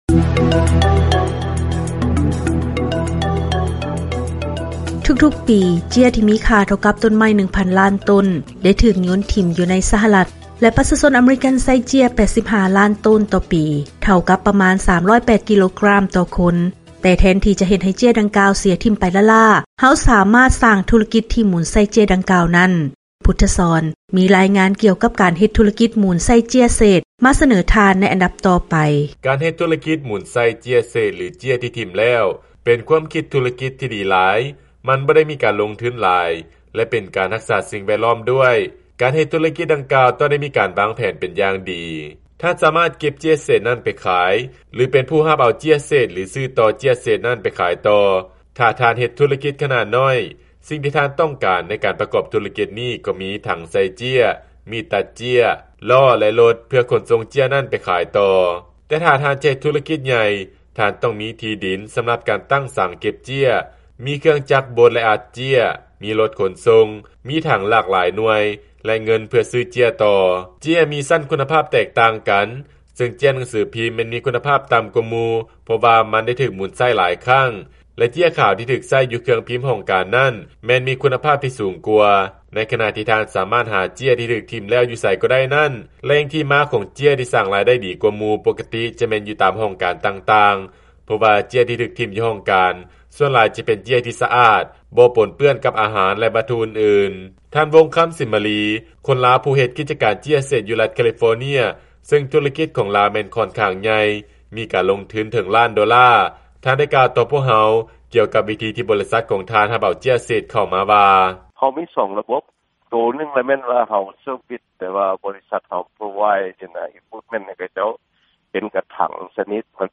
ຟັງລາຍງານ ທຸລະກິດໝູນໃຊ້ເຈ້ຍເສດ ສາມາດເປັນອາຊີບ ທີ່ສ້າງລາຍໄດ້ດີ